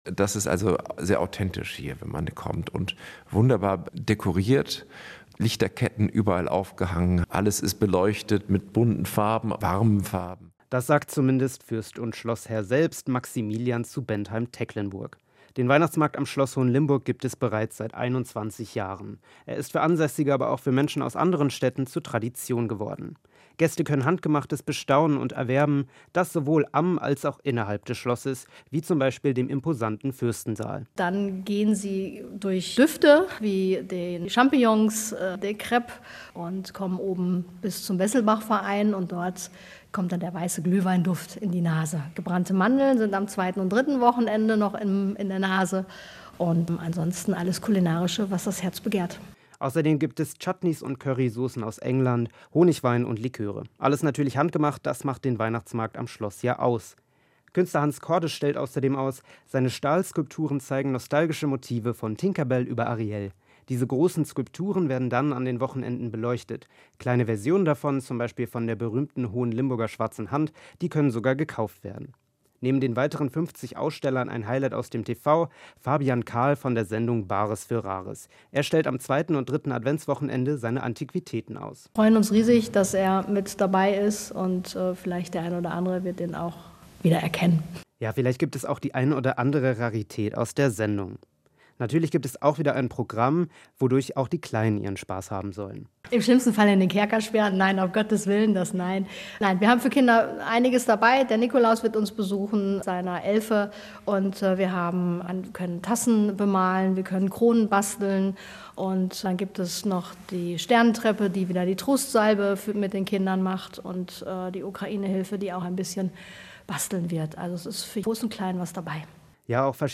beitrag-weihnachtsmarkt-schloss-hohenlimburg.mp3